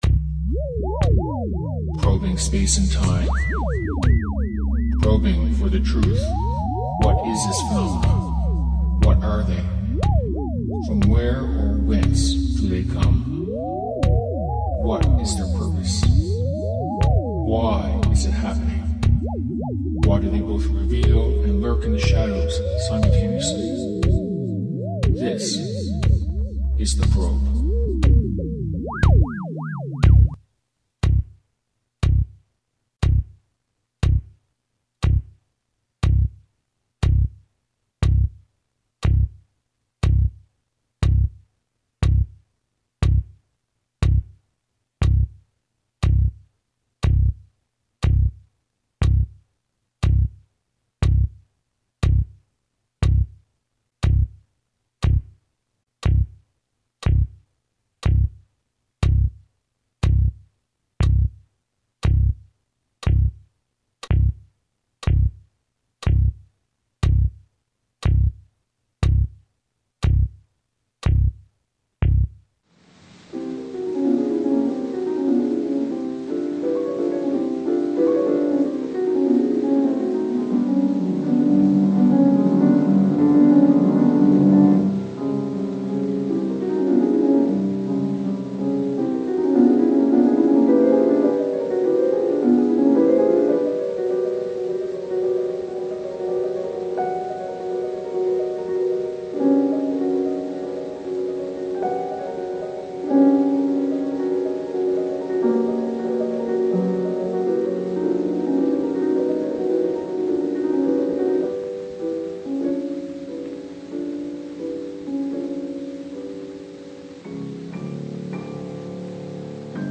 UFO Talk Show